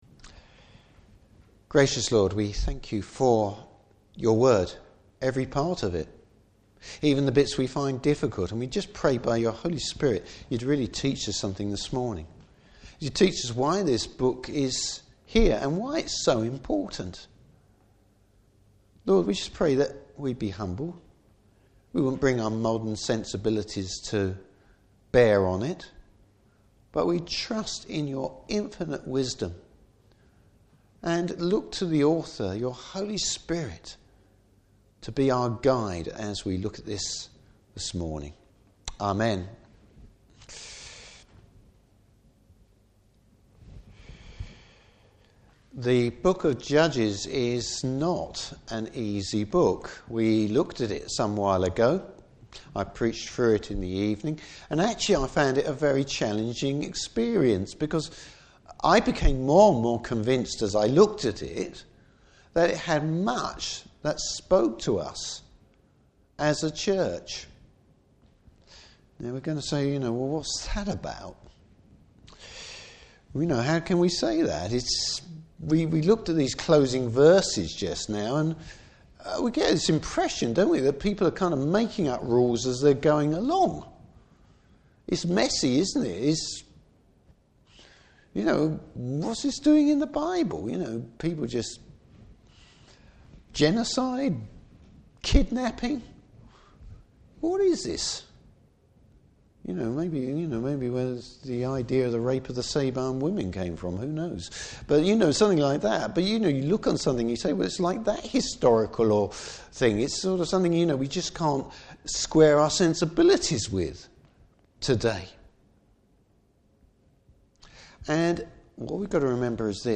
Service Type: Morning Service How the book of Judges points us to the need of God’s King!